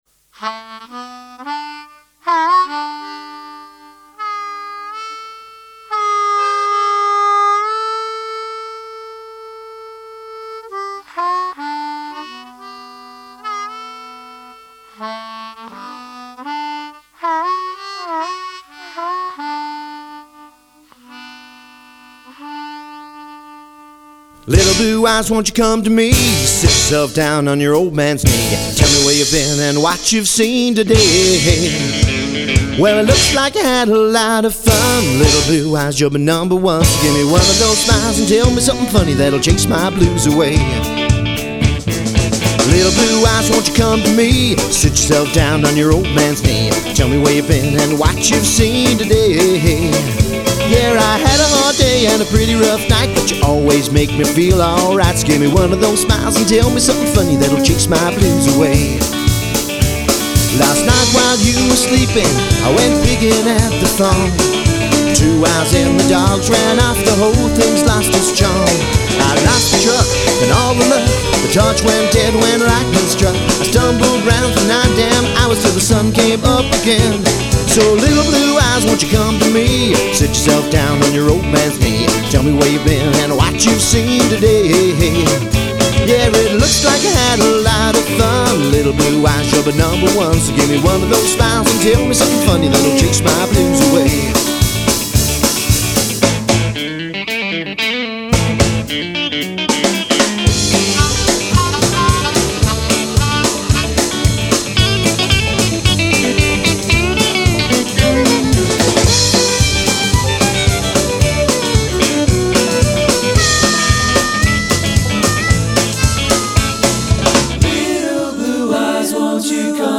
tongue-in-cheek rockers
country music